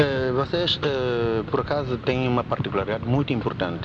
SpokenPortugueseGeographicalSocialVarieties_splits
Automatic Speech Recognition